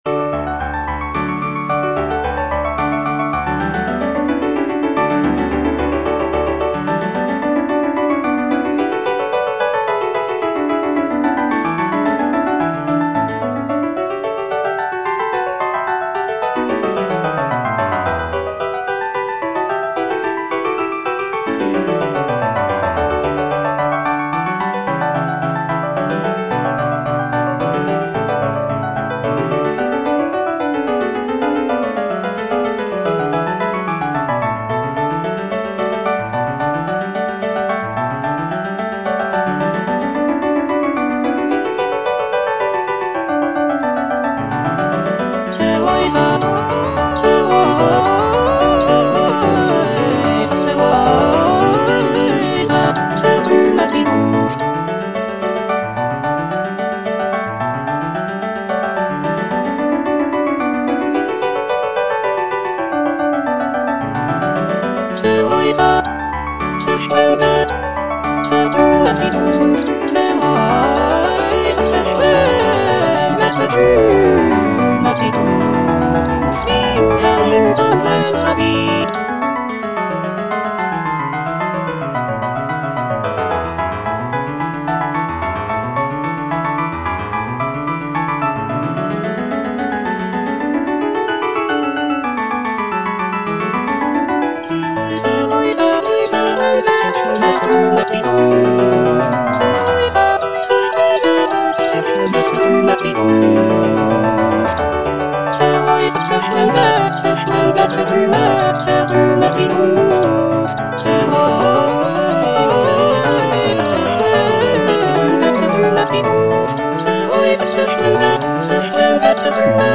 CANTATA
Alto (Emphasised voice and other voices) Ads stop